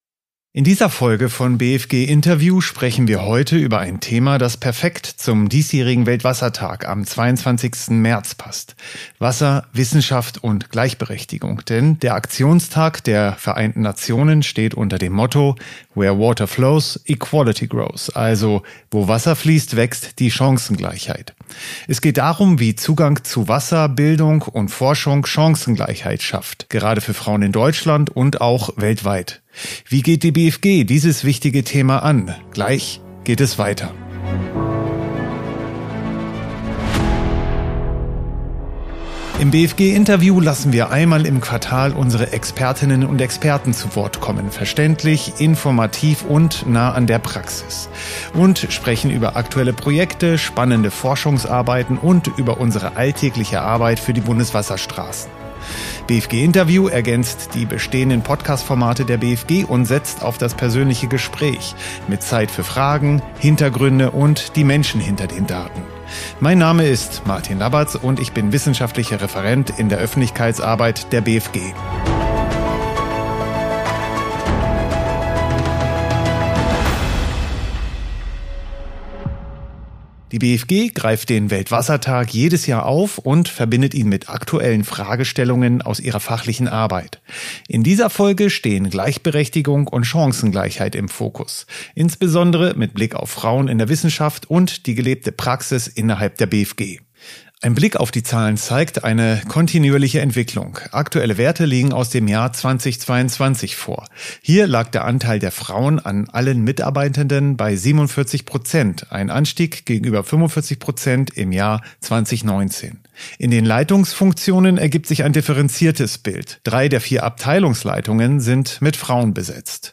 Wie Wissenschaftlerinnen die Gewässerkunde prägen ~ BfG Interview Podcast